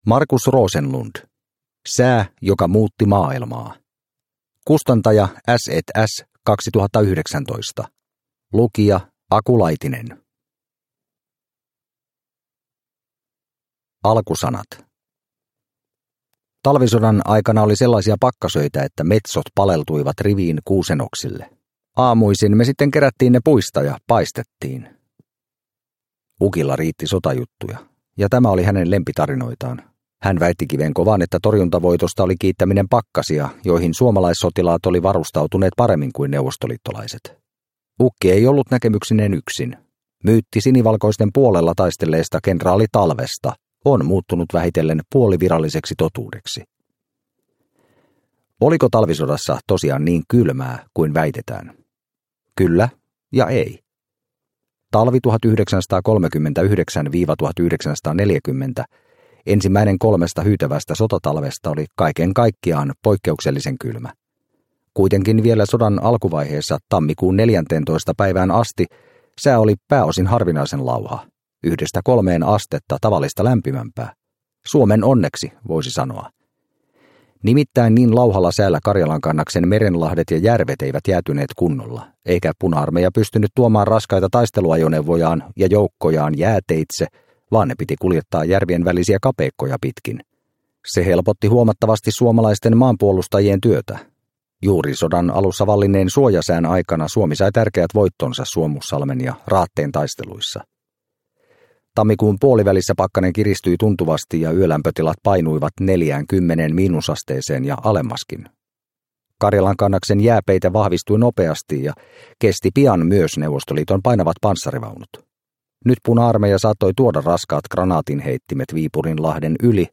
Sää joka muutti maailmaa – Ljudbok – Laddas ner